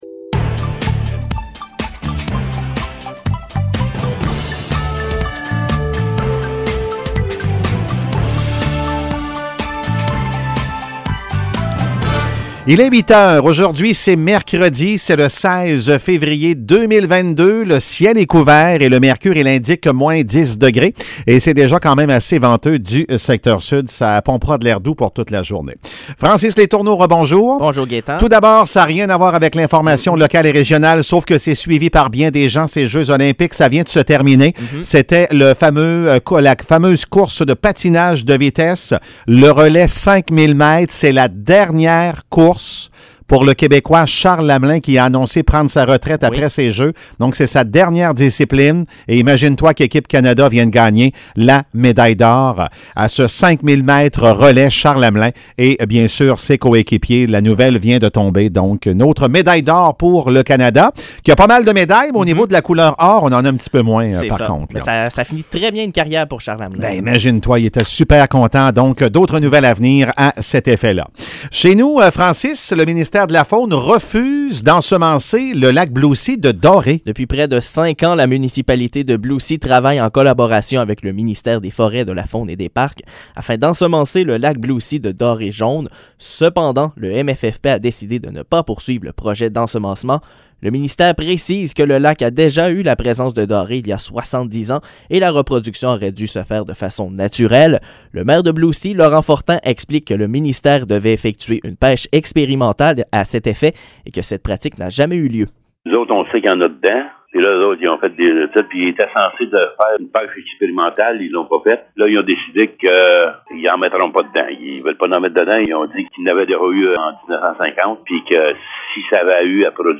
Nouvelles locales - 16 février 2022 - 8 h